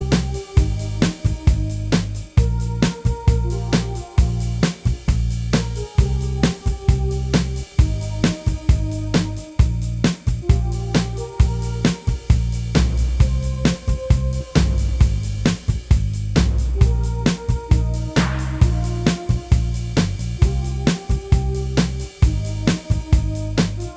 No Guitars Pop (2010s) 2:53 Buy £1.50